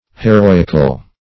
Heroical \He*ro"ic*al\, a.